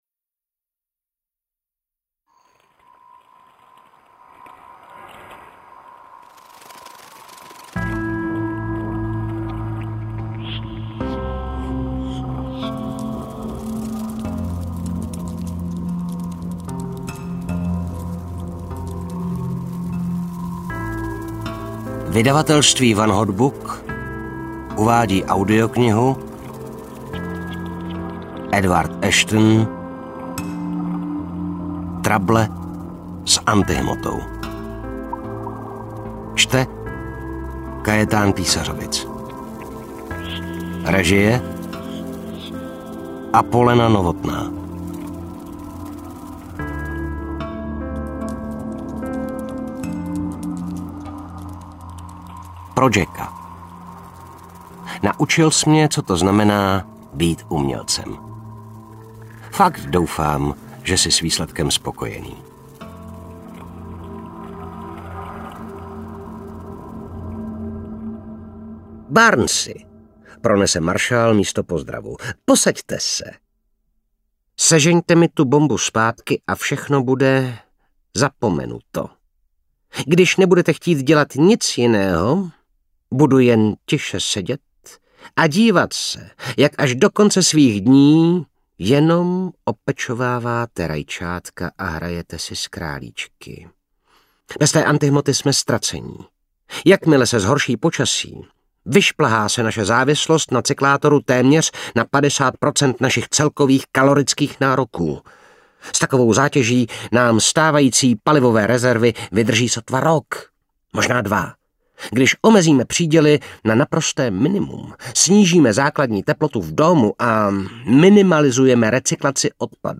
Trable s antihmotou audiokniha
Ukázka z knihy